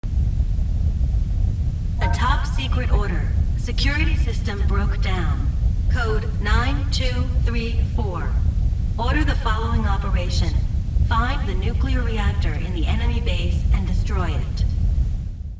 Retransmisión de la misión, final de fase